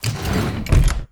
close2.wav